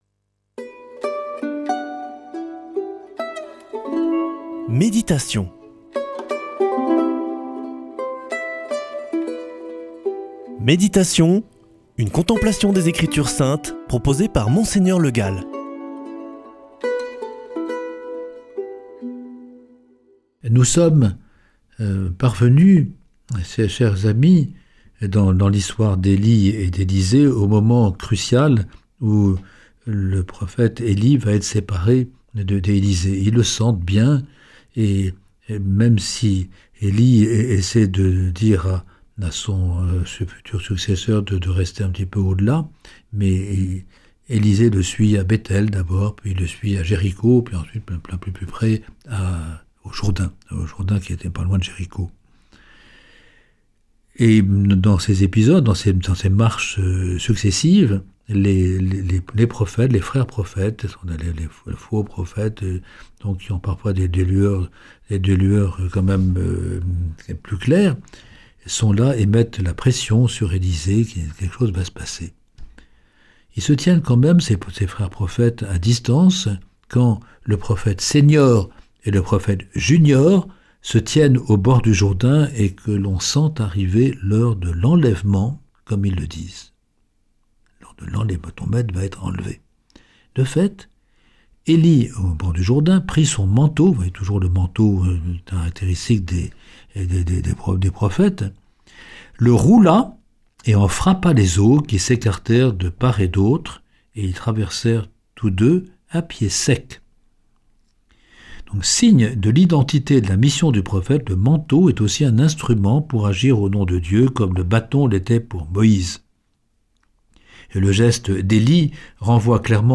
Méditation avec Mgr Le Gall
Une émission présentée par
Présentateur